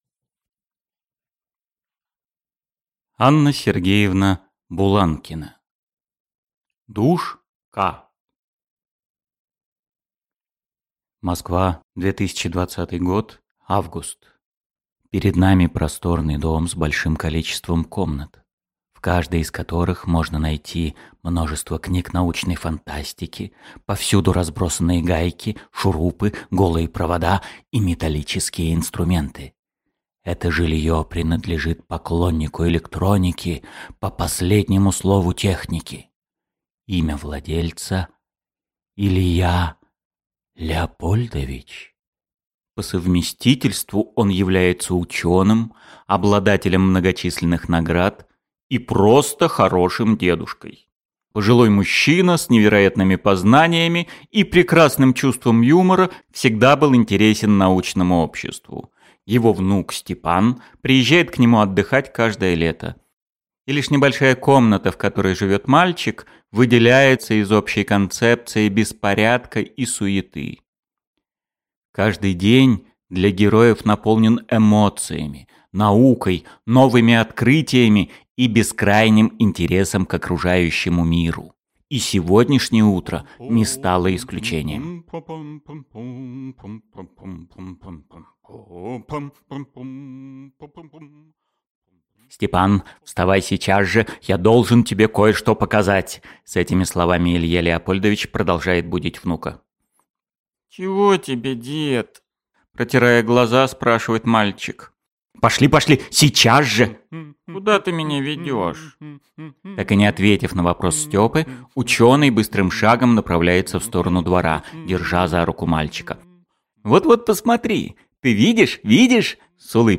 Аудиокнига Душка | Библиотека аудиокниг